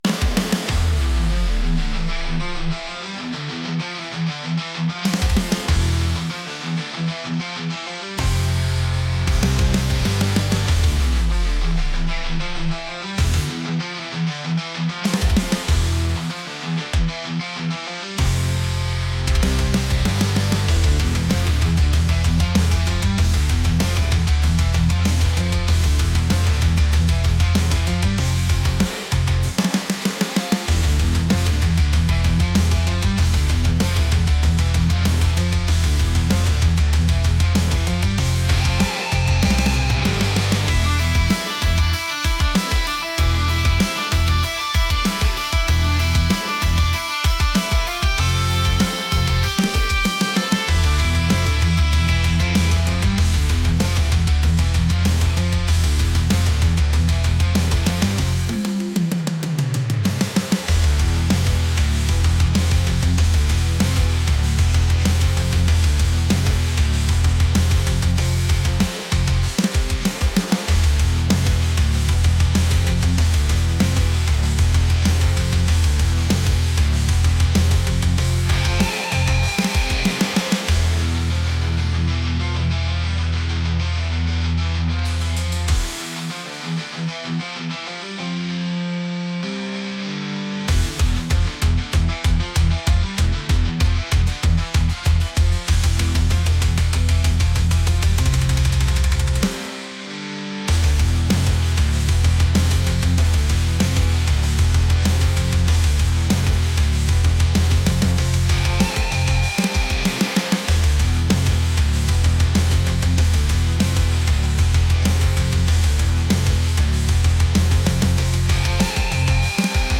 heavy | aggressive